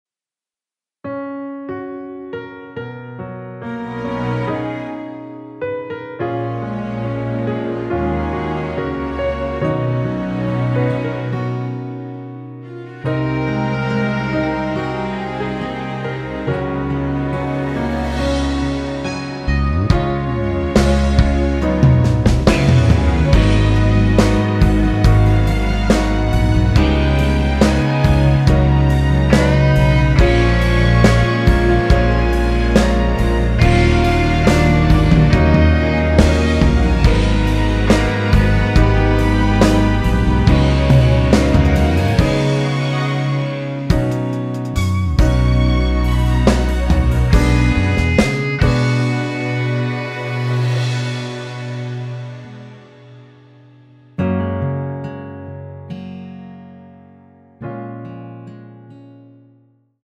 Gb
앞부분30초, 뒷부분30초씩 편집해서 올려 드리고 있습니다.
중간에 음이 끈어지고 다시 나오는 이유는